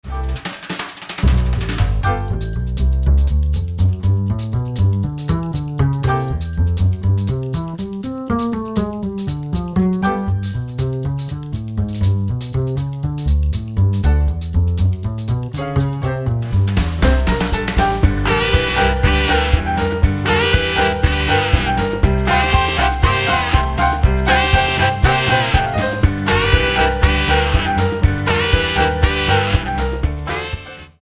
120 BPM